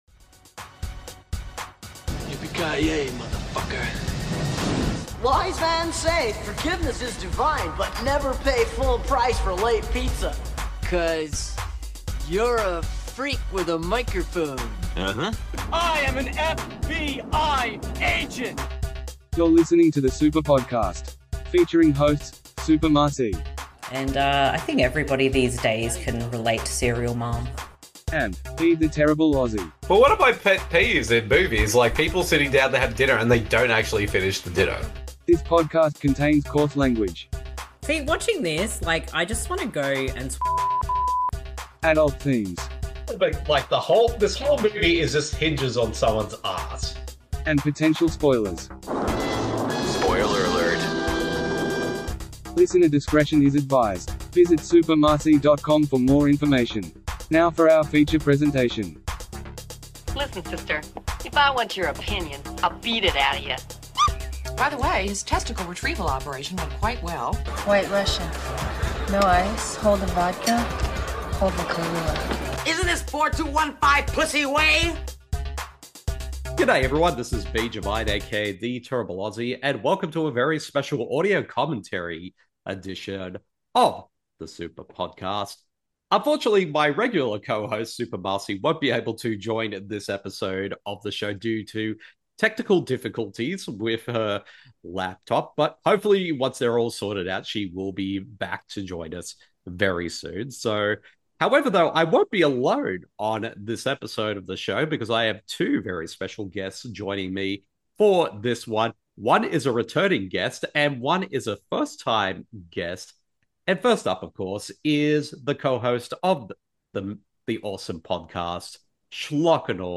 The Super Podcast Audio Commentary Top Secret! (1984) Val Kilmer Tribute